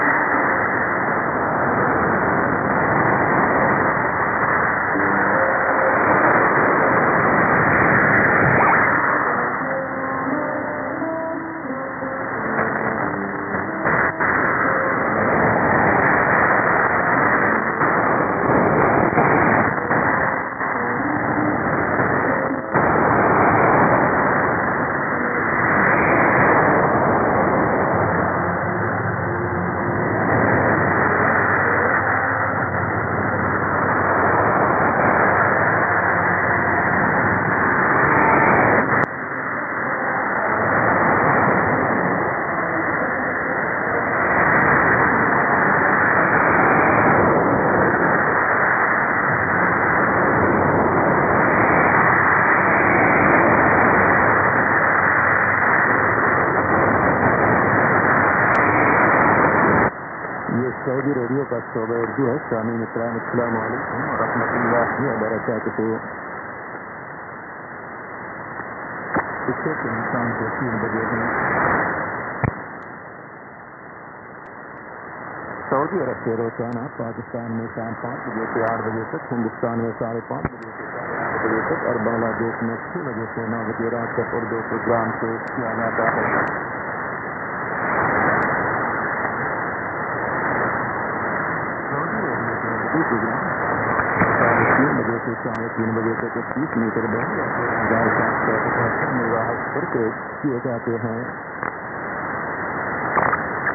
IS: interval signal
ID: identification announcement